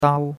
dao1.mp3